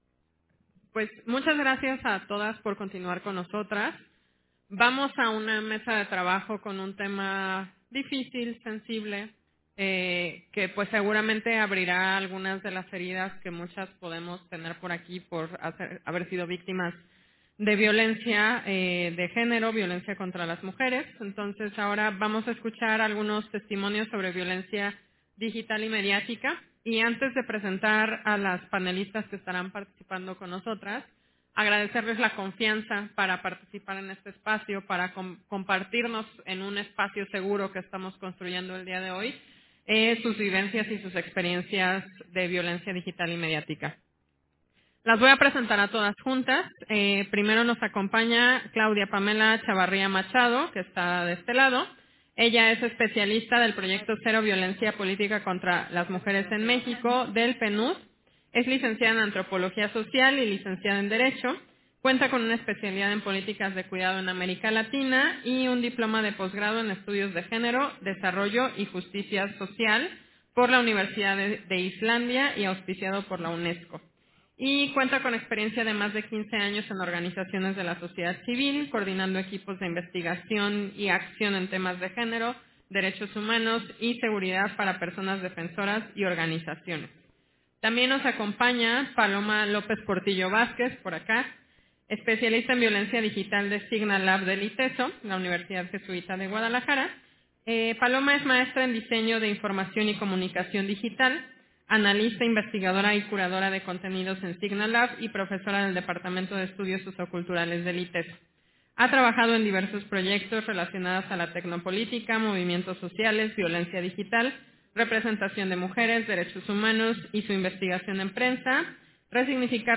Versión estenográfica de la mesa de trabajo: Testimonios sobre violencia digital y mediática, en el marco del evento, Impacto de la violencia digital y mediática en las mujeres políticas